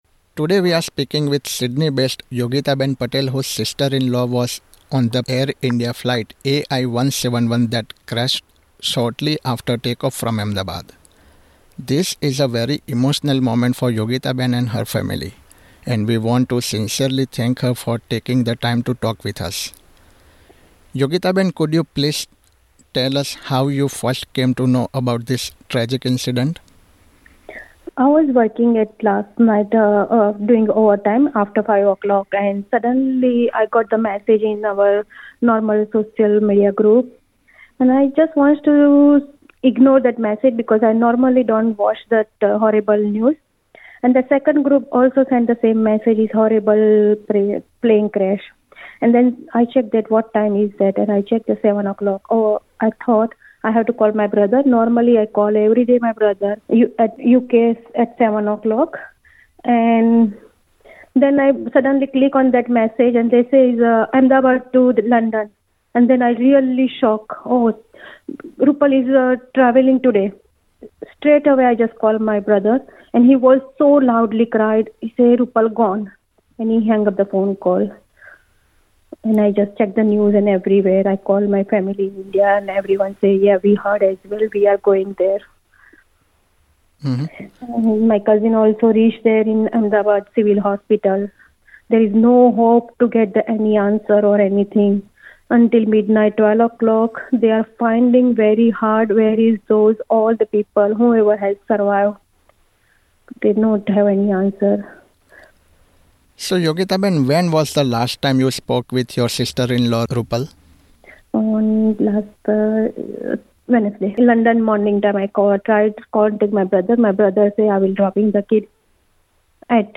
Speaking with SBS Gujarati, she said that the entire family is in shock and still looking for answers.